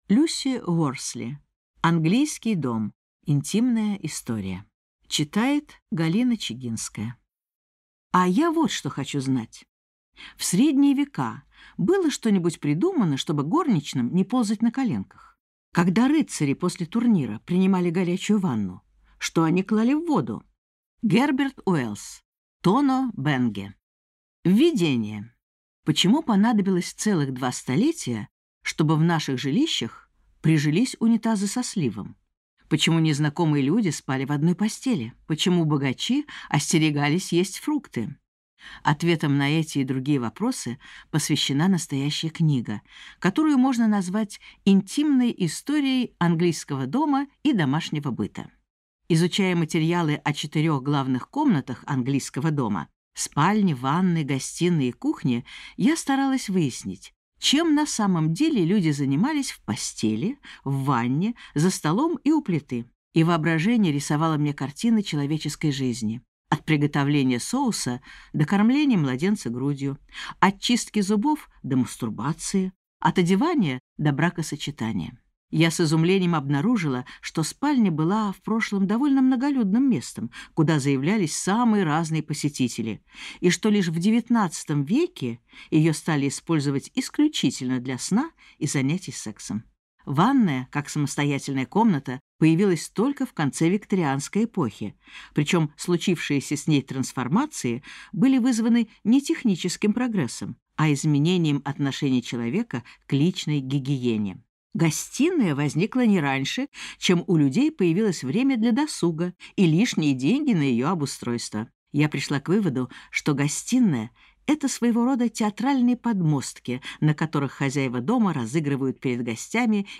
Аудиокнига Английский дом. Интимная история | Библиотека аудиокниг